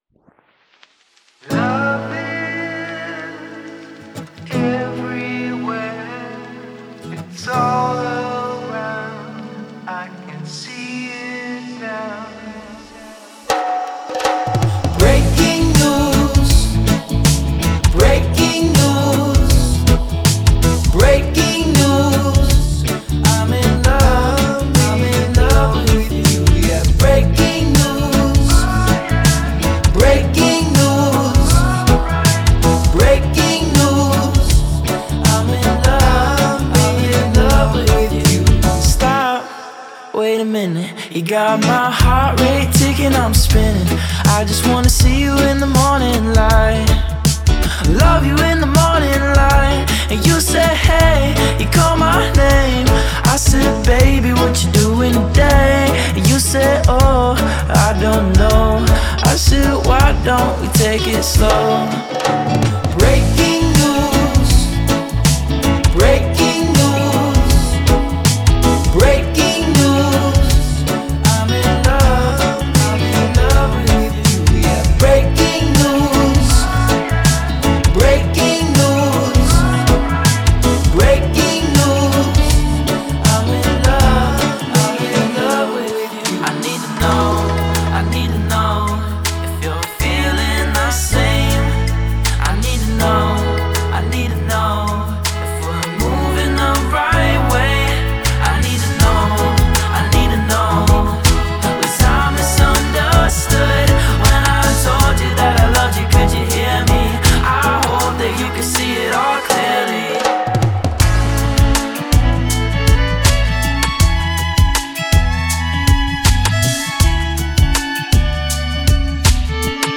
reggae inspired, feel good musical project
violin